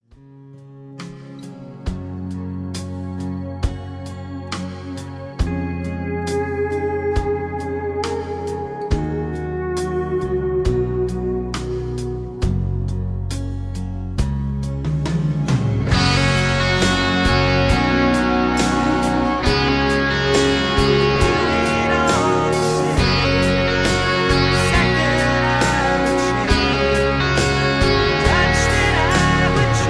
karaoke , mp3 backing tracks